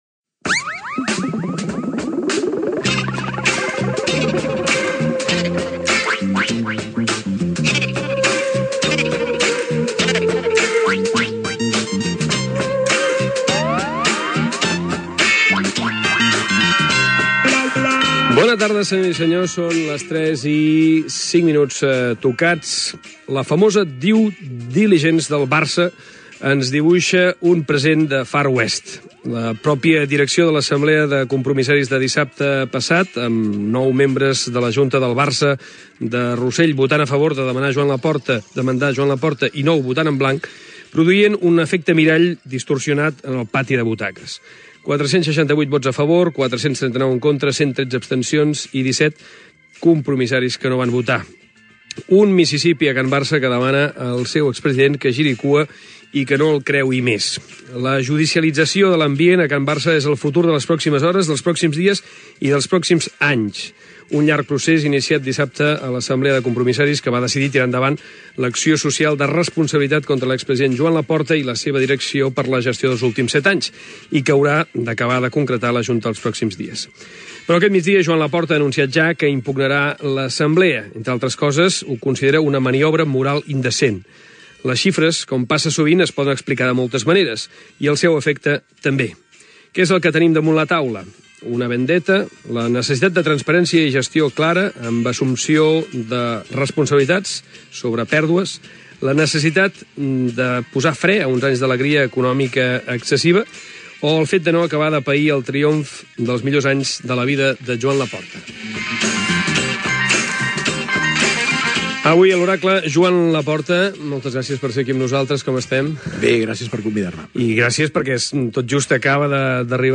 La gestió del president del Futbol Club Barcelona Joan Laporta amb una entrevista a l'estudi,
Intervenció dels tertulians del programa